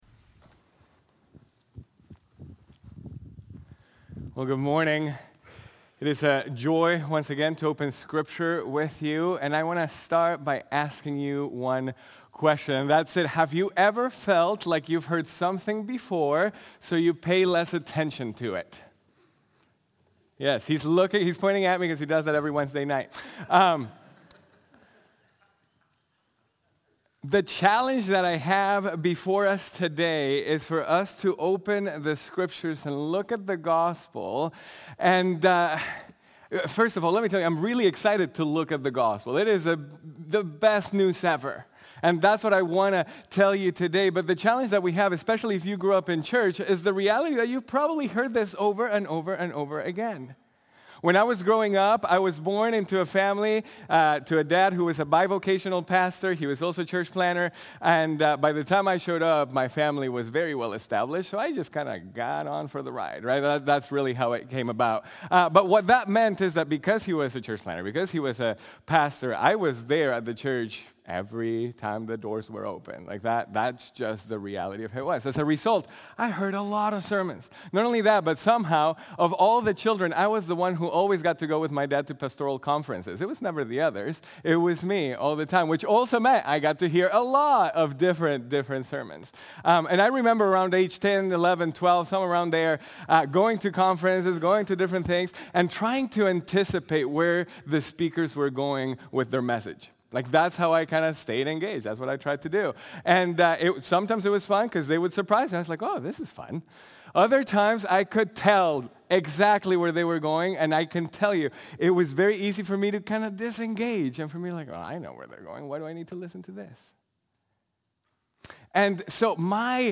Dedicated To The Gospel Of God | Baptist Church in Jamestown, Ohio, dedicated to a spirit of unity, prayer, and spiritual growth